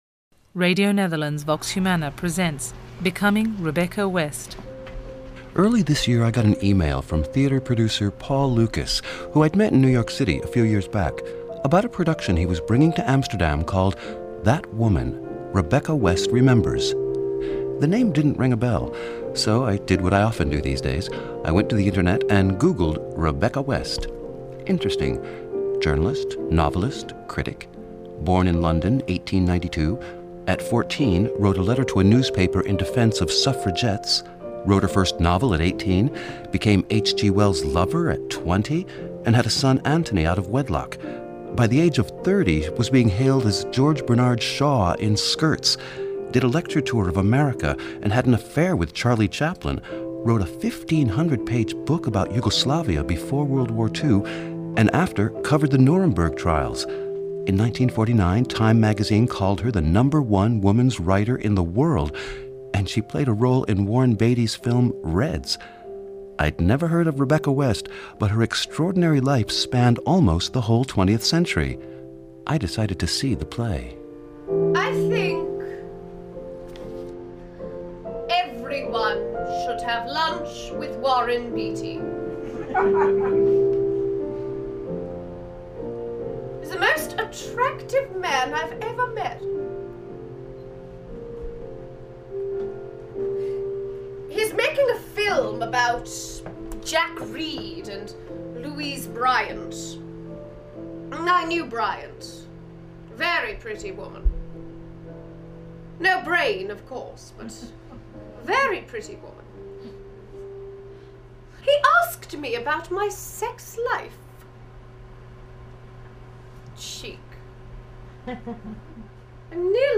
A portrait of British journalist and writer Rebecca West (1892-1983), whose life spanned most of the 20th century, with memories from her great-niece and thoughts from an actress who portrays her on stage.